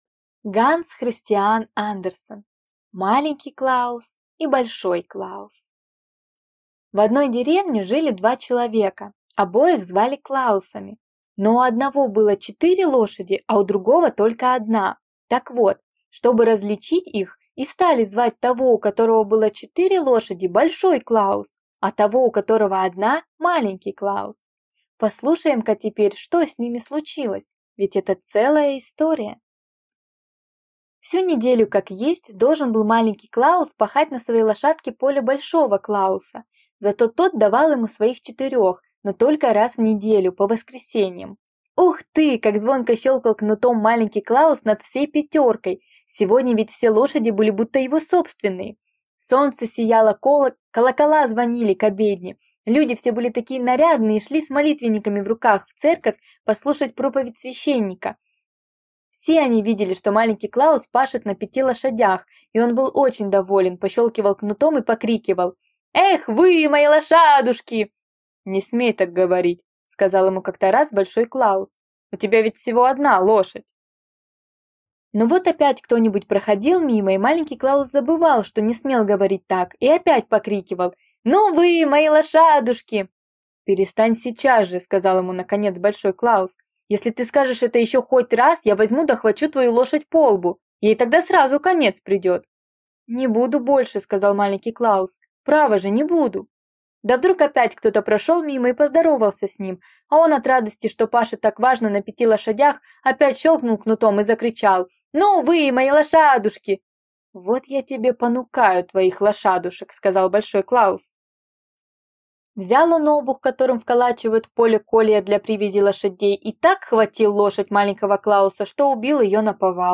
Аудиокнига Маленький Клаус и большой Клаус | Библиотека аудиокниг